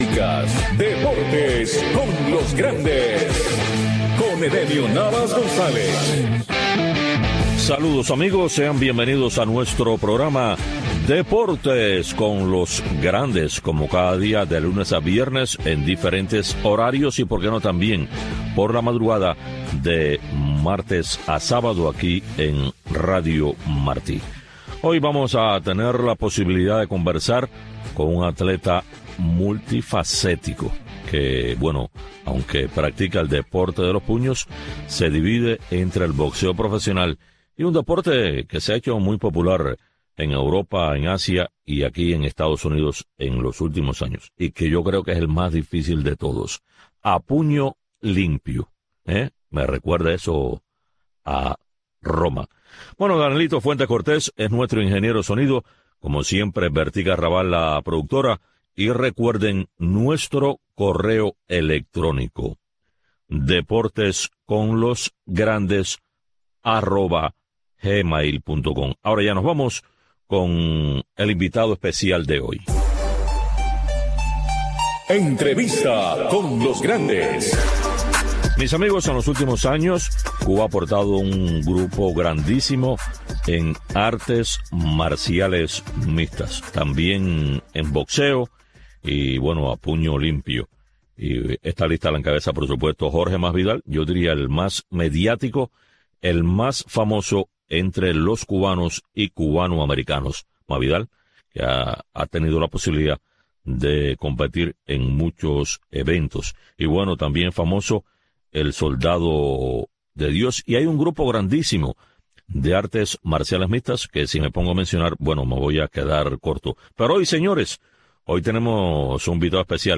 Deportes con los grandes. Un programa de Radio Marti, especializado en entrevistas, comentarios, análisis de los grandes del deporte.